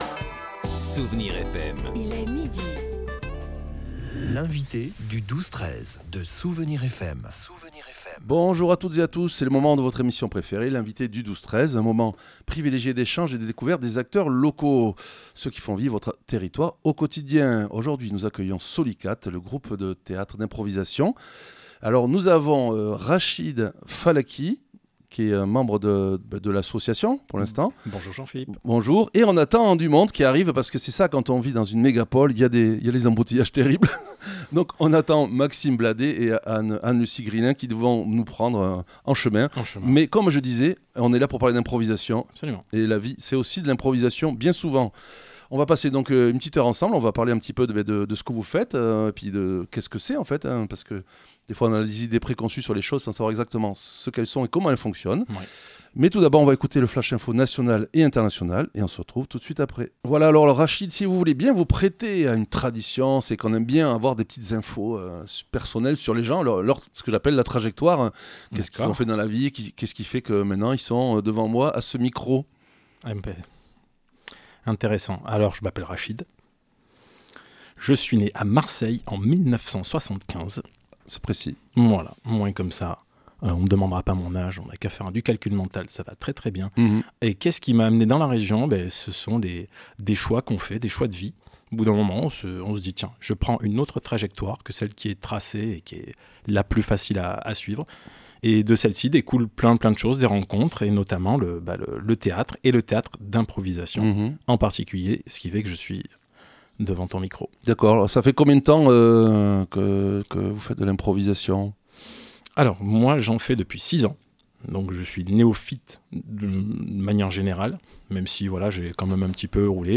L'invité(e) du 12-13 de Soustons recevait aujourd'hui SOLYCAT , troupe d'improvisation théâtrale.